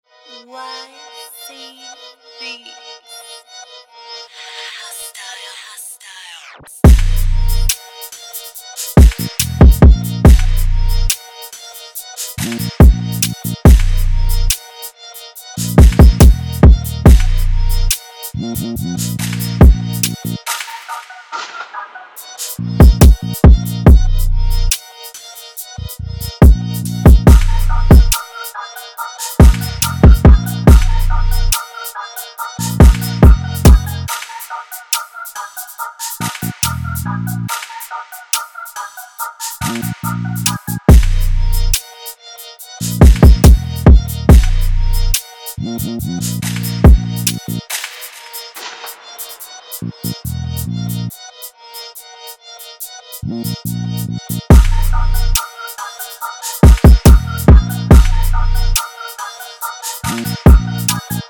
• 20 Melody Loops